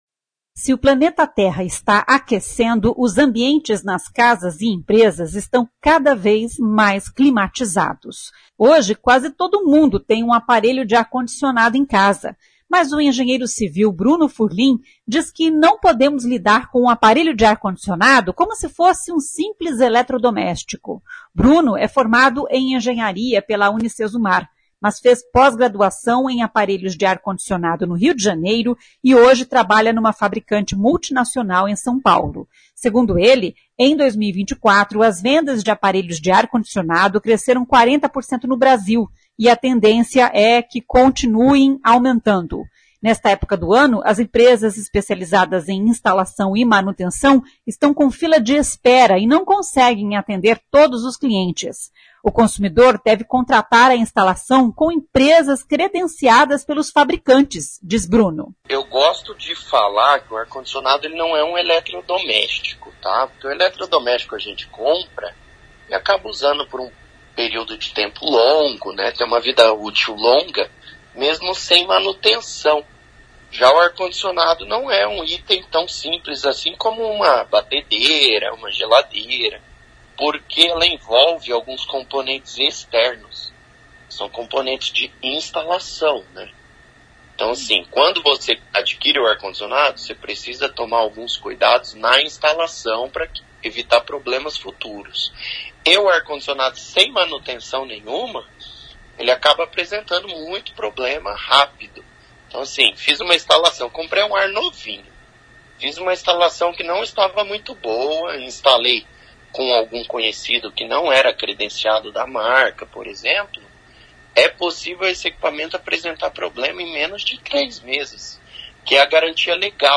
A CBN conversou com um engenheiro especializado em aparelhos de ar condicionado.